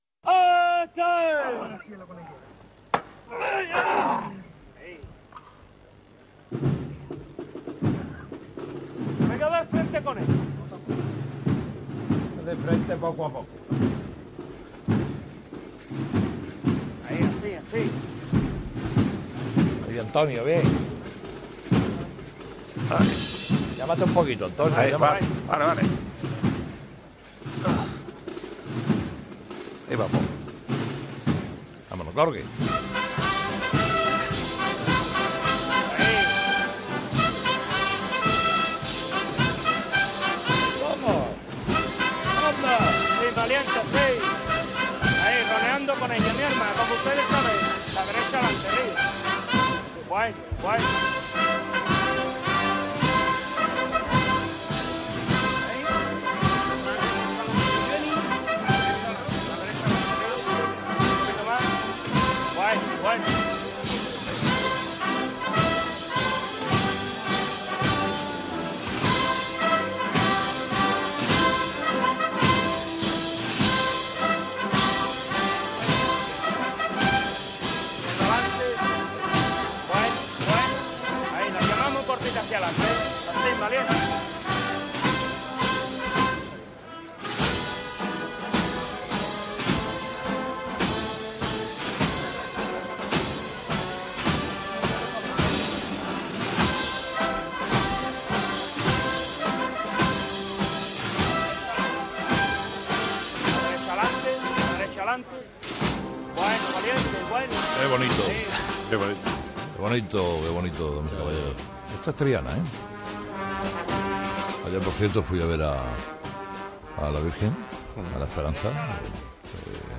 Carlos Herrera ilustra a los tertulianos en las marchas de Semana Santa
Suena entonces, 'Aurora de Santa Marina' de Abel Moreno Gómez.